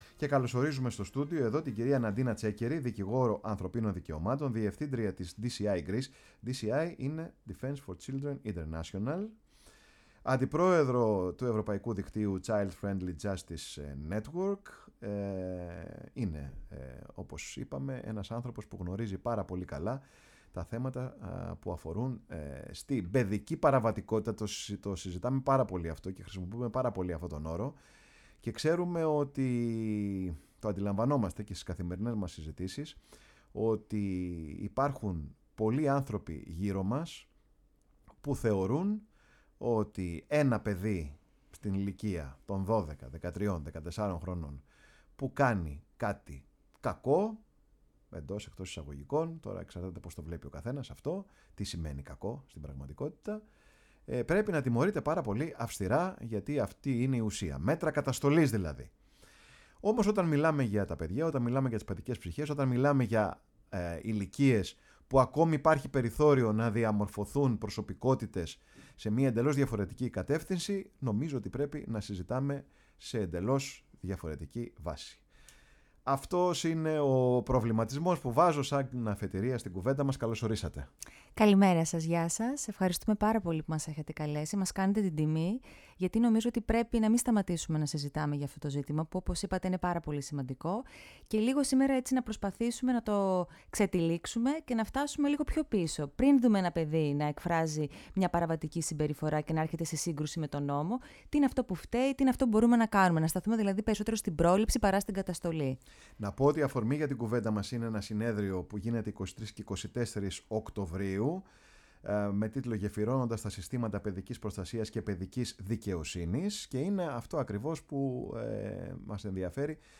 φιλοξένησε σήμερα στο στούντιο η εκπομπή ”Πάρε τον Χρόνο σου”